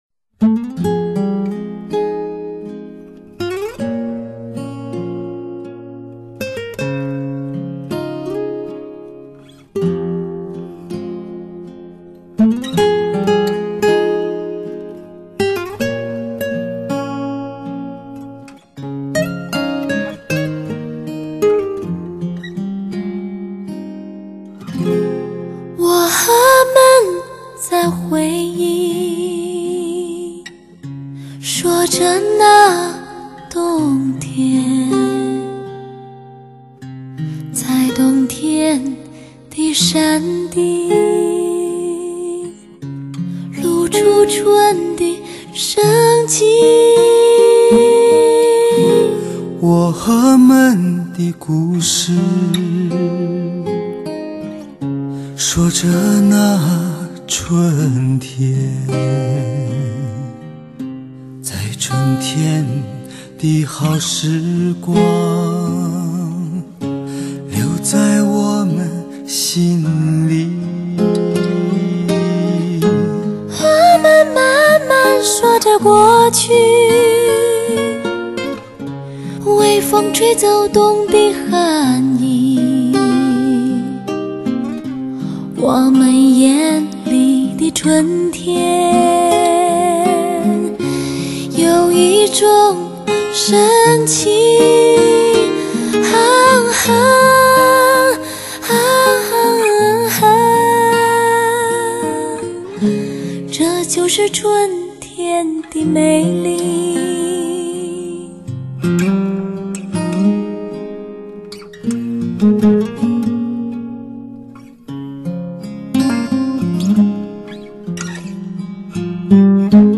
6.1DTS CD发烧极品，至高境界，真环绕声体验。